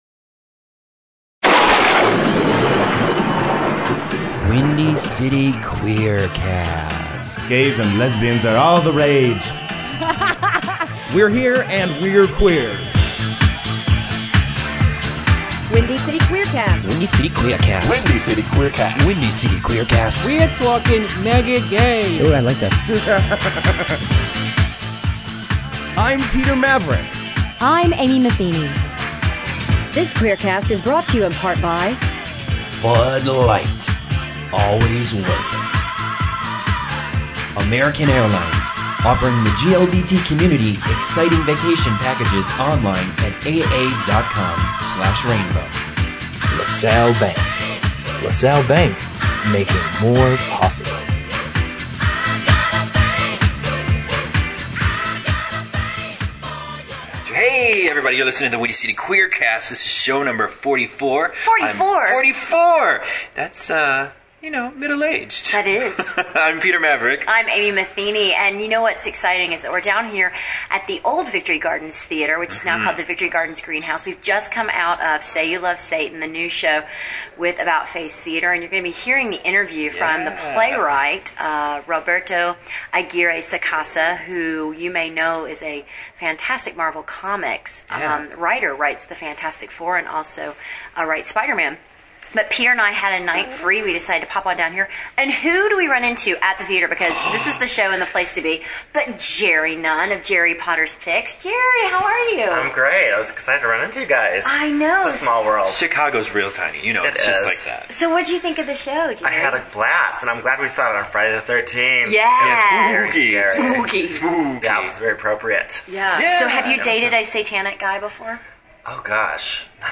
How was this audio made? We're live and on the scene of Say You Love Satan